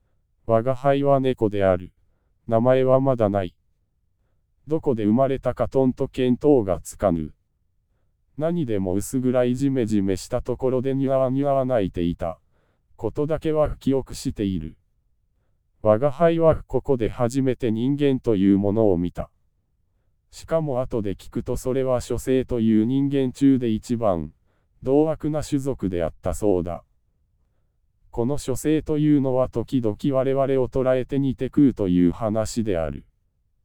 UTF-8の日本語テキストを音声に変換してくれる。